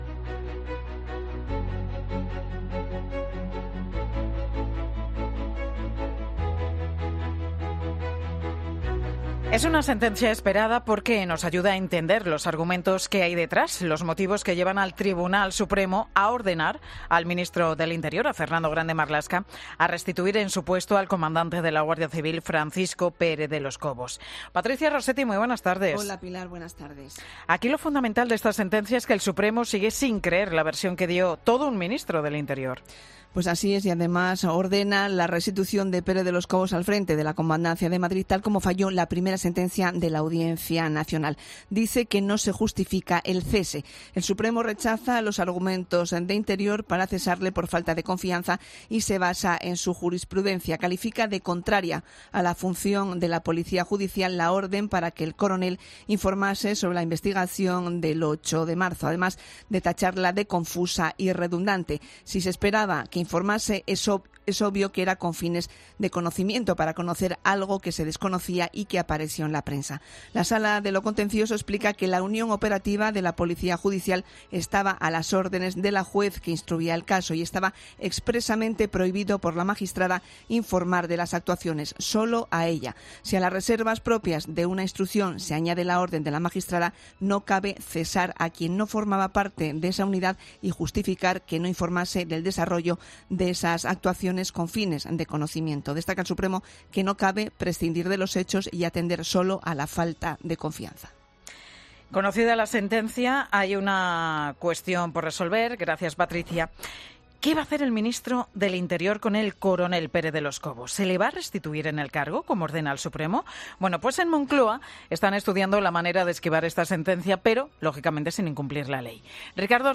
Los argumentos de la sentencia del Supremo sobre el coronel Pérez de los Cobos en 'Mediodía COPE'